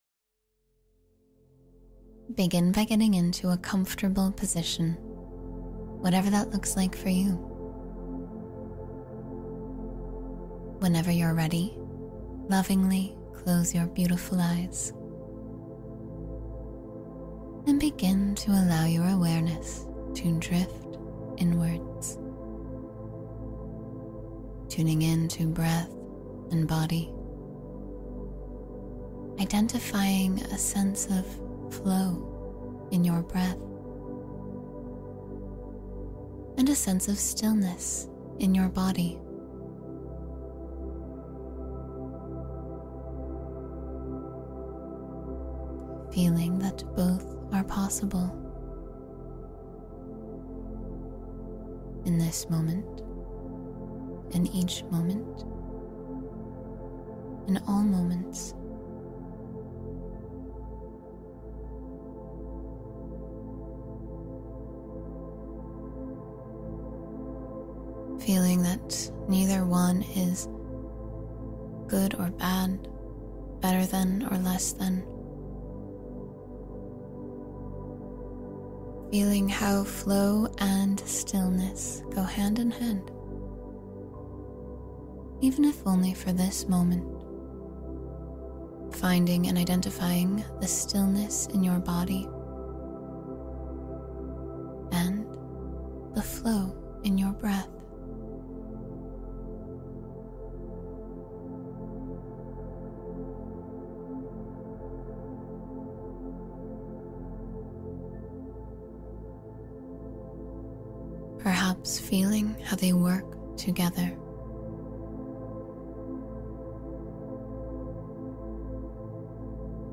Open Yourself to Receive Abundance — Meditation for Financial Prosperity